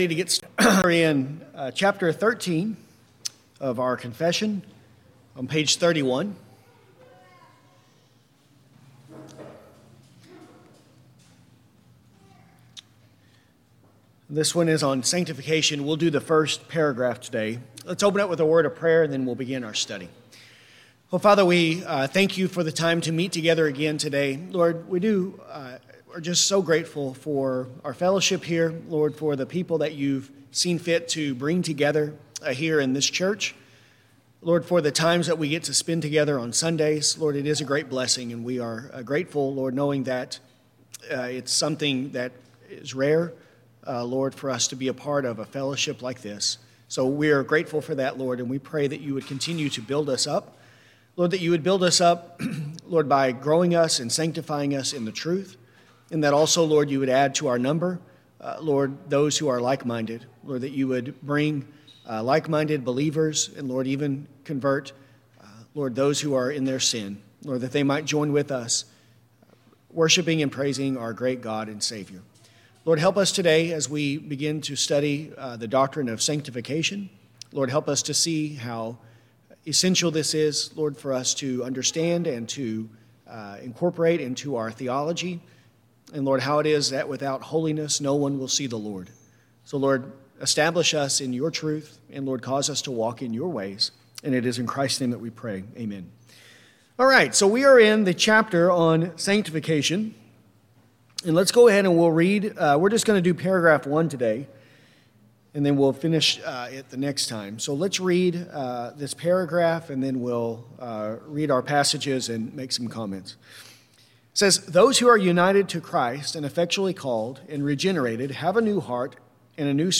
This lesson covers Paragraph 13.1. To follow along while listening, use the link below to view a copy of the confession.